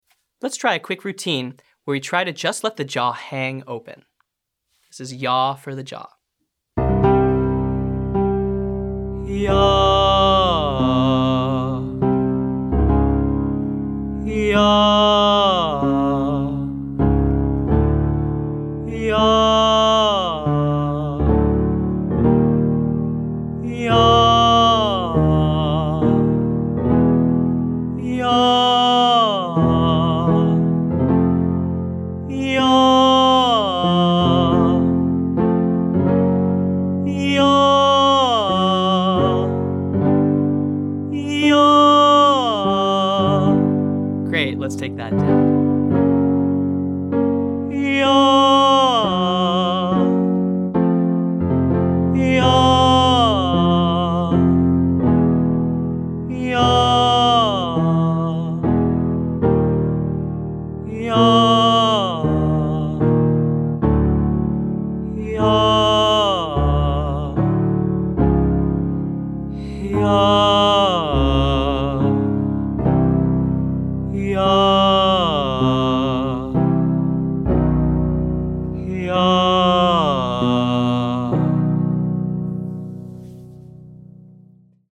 Day 4: Vocal Tension Release - Online Singing Lesson